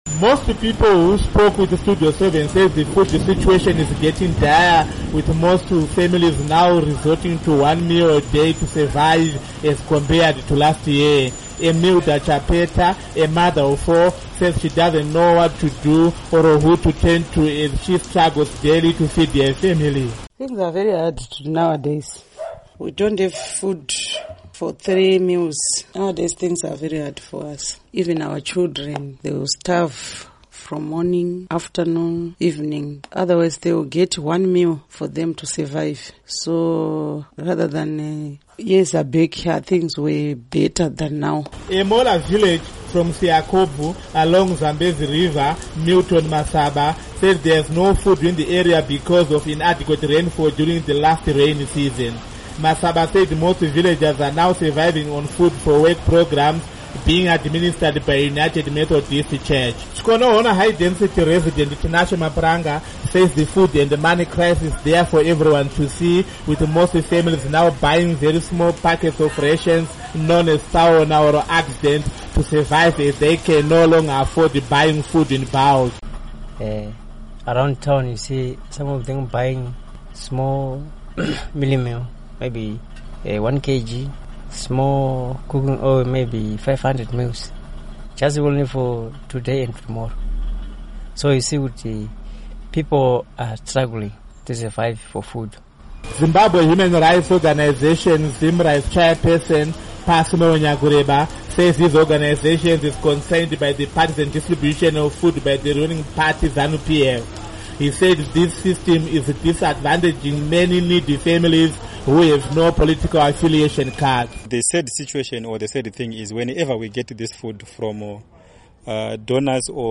Zimbabwe Food Shortages